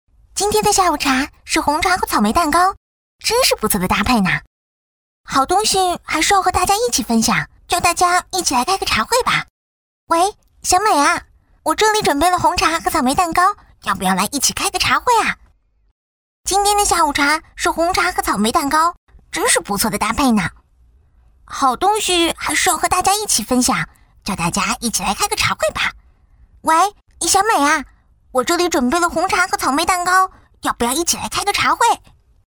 女29-角色扮演【甜甜-甜美可爱】
女29-大气磁性 素人自然